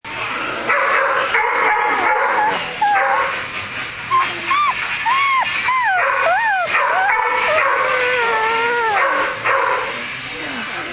Sound Effects
Barking Whining